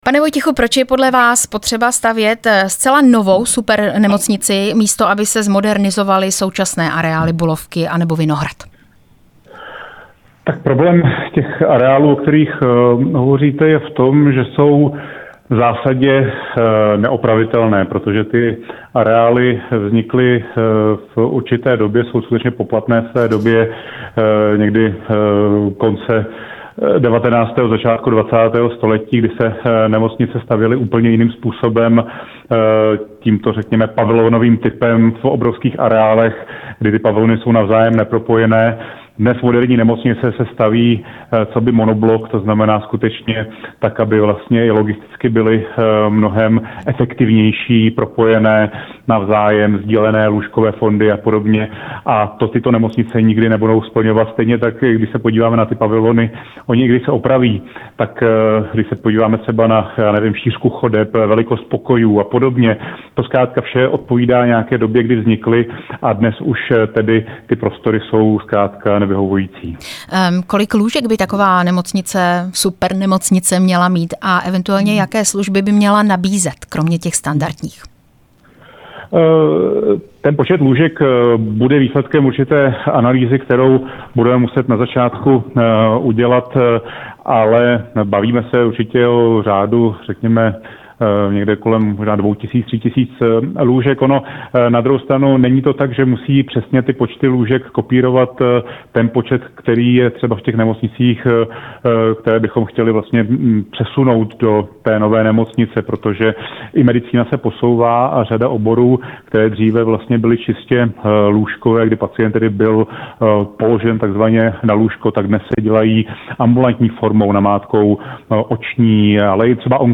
Rozhovory
Rozhovor s exministrem zdravotnictví Adamem Vojtěchem